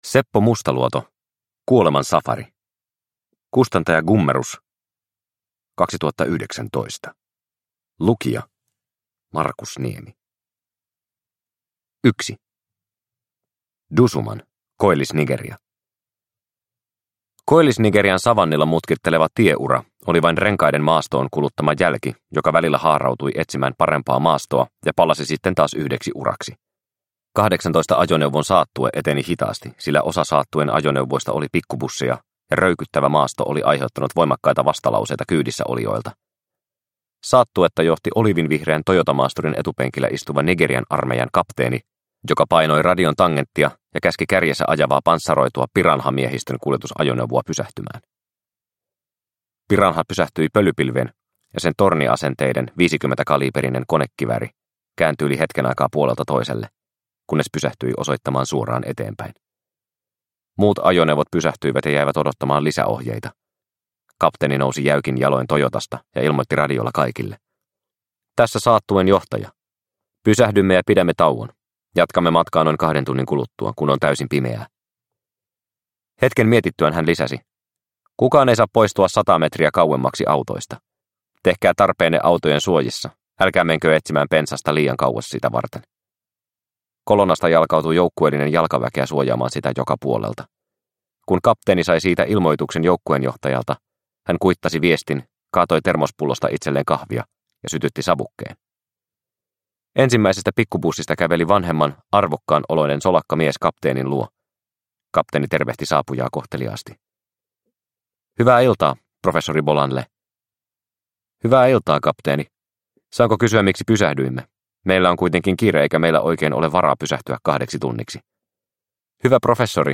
Kuoleman safari – Ljudbok – Laddas ner